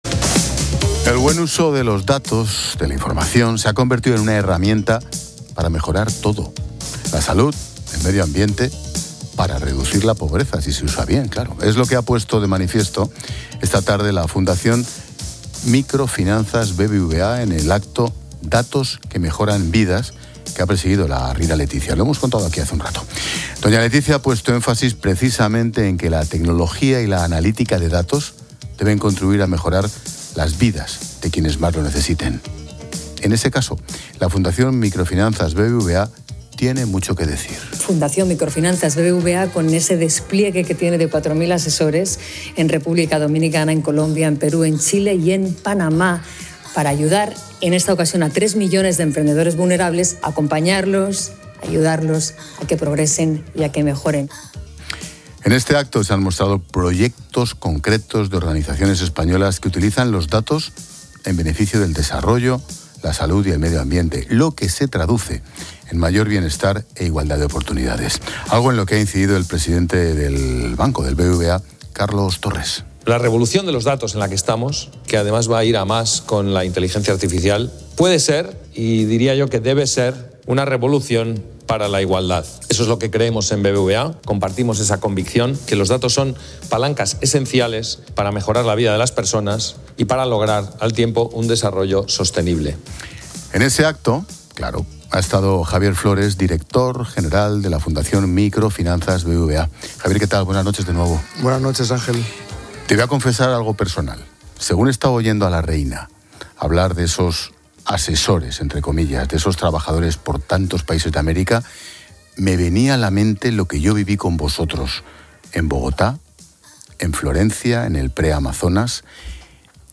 En una entrevista en ‘La Linterna’ de COPE